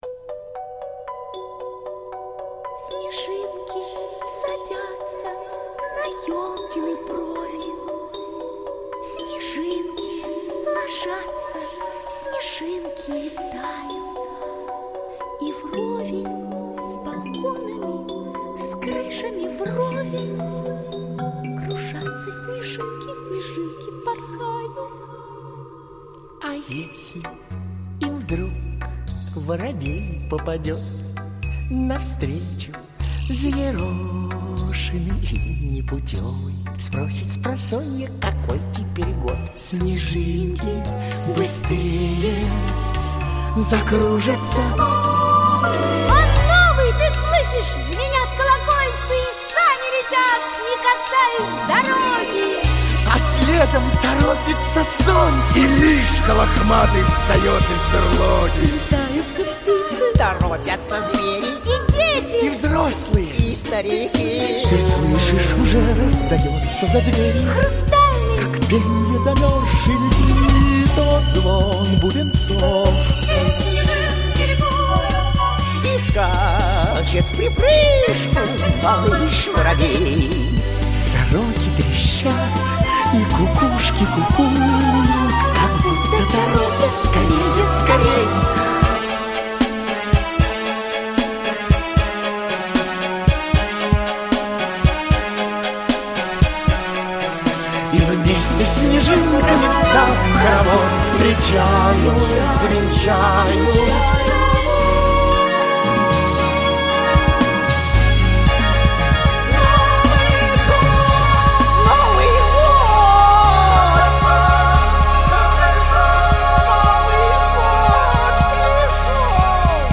Снежинки - песня про зиму - слушать онлайн